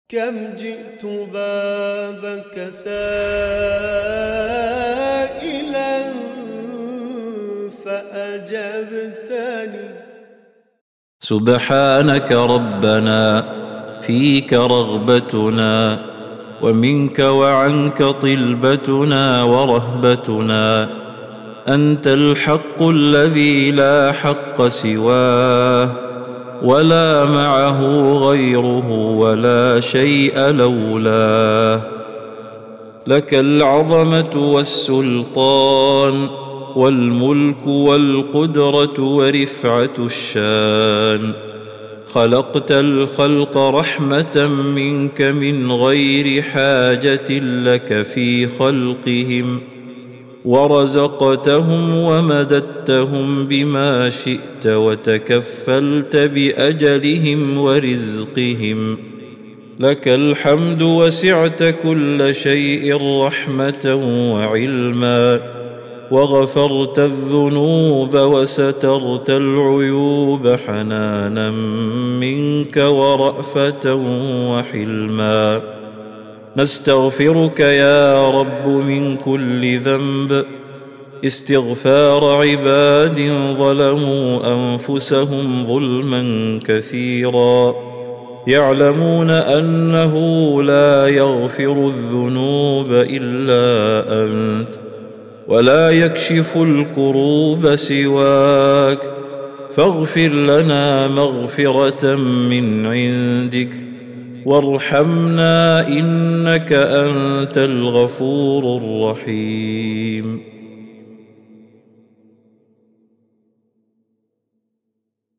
دعاء خاشع ومؤثر يعبر عن التذلل والافتقار الكامل لله تعالى، معترفاً بفضله ورحمته الواسعة. يسلط الضوء على صفات الله العليا من خلقه ورزقه ومغفرته، وينتهي بالرجاء الصادق في مغفرته ورحمته.